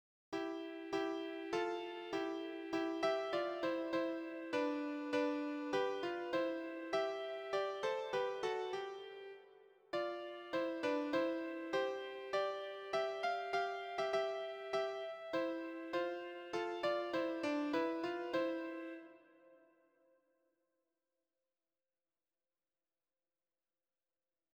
Répétition SATB par voix
Soprane
Sancta Maria_soprano_alto.mp3